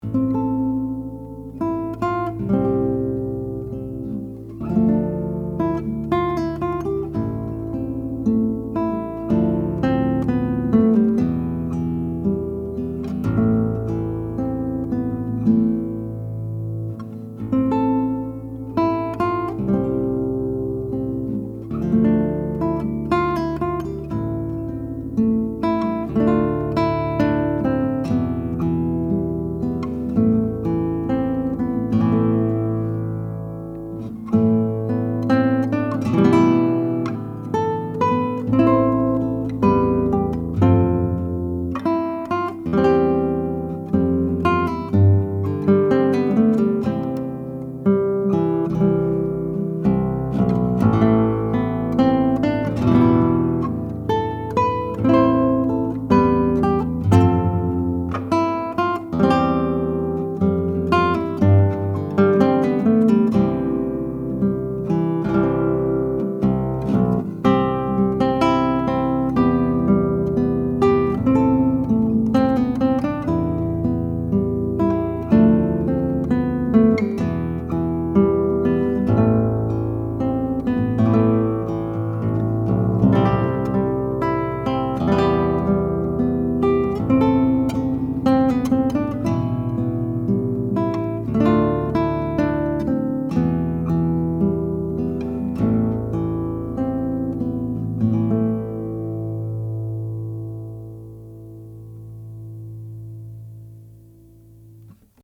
Here are 19 very quick, 1-take MP3 sound files of me playing this guitar, to give you an idea of what to expect. The guitar has amazing sympathetic resonance and sustain, as well as good power and projection, beautiful bass responce, and a very even response across the registers. These MP3 files have no compression, EQ or reverb -- just straight signal, tracked through a Blue Woodpecker ribbon mic, into a Presonus ADL 600 mic preamp, into a Sony PCM D1 flash recorder.
English Renaissance)